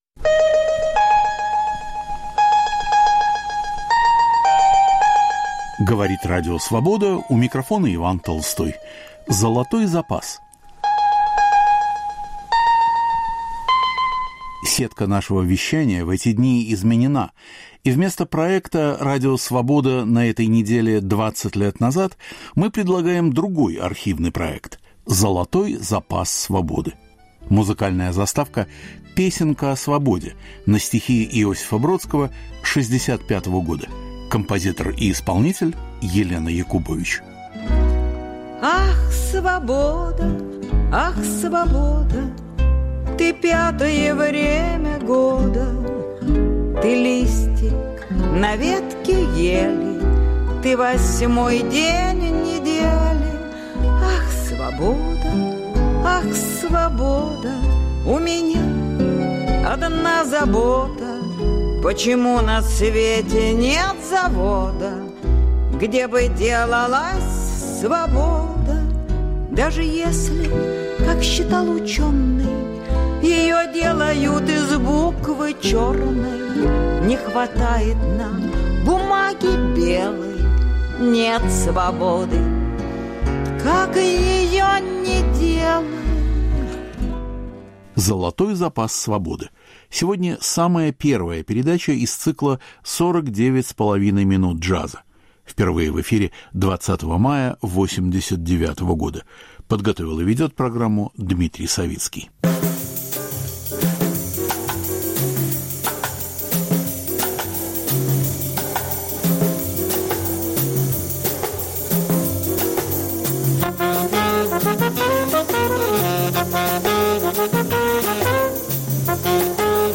пианист